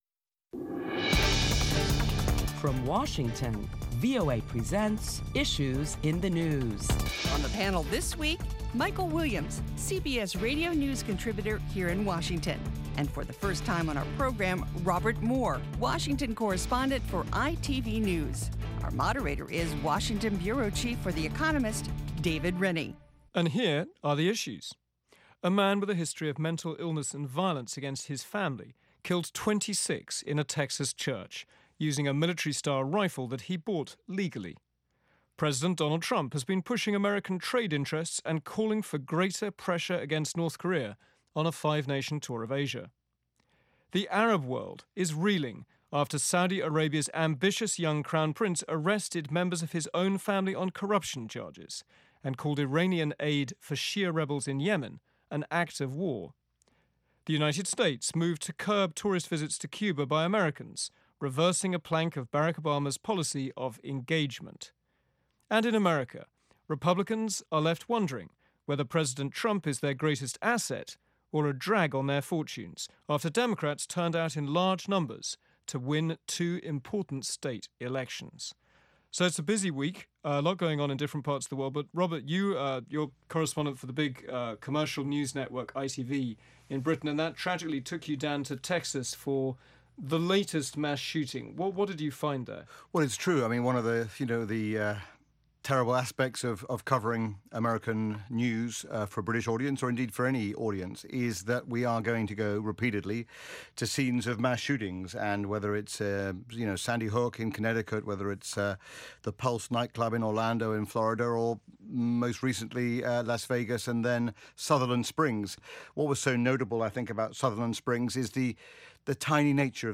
This week on Issues in the News, prominent Washington correspondents discuss topics making headlines around the world including the mass shooting in a small Texas town, and how that may spark renewed debate on gun laws.